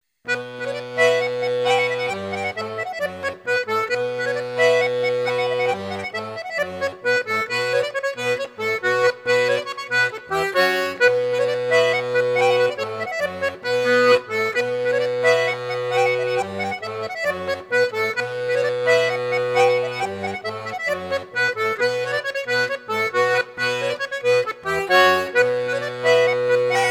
danse : branle
Pièce musicale éditée